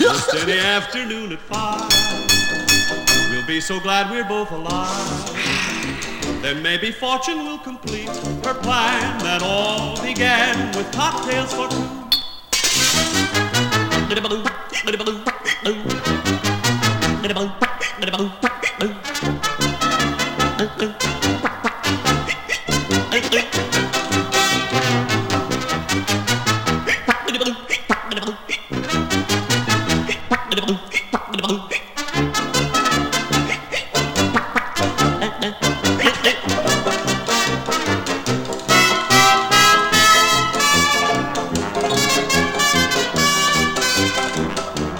Jazz, Comedy　USA　12inchレコード　33rpm　Mono